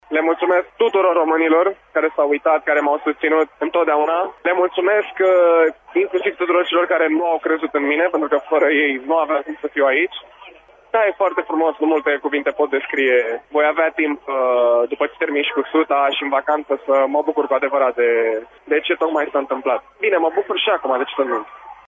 Noul campion olimpic a vorbit, reporterilor Radio România, cu o sinceritate extraordinară despre sentimentele trăite: